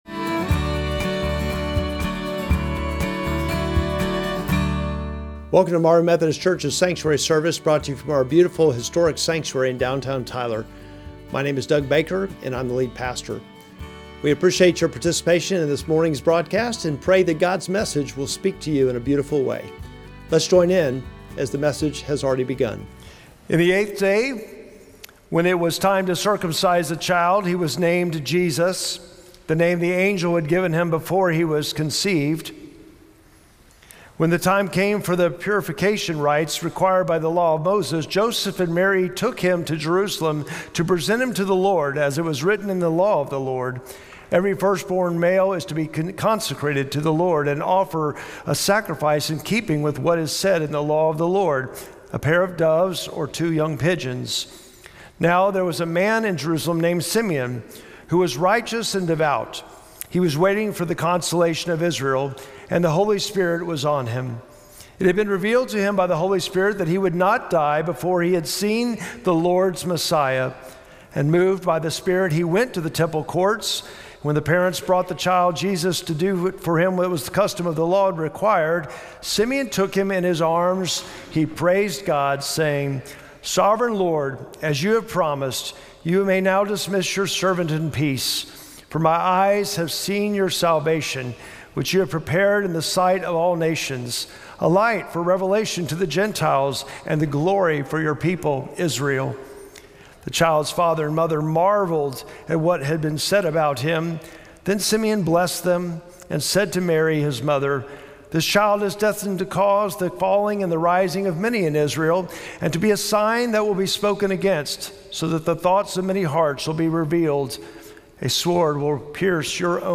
Sermon text: Luke 2:21-38